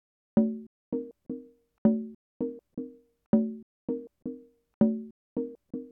• bongos and congas resample 2.wav
bongos_and_congas_sample_2_E0l.wav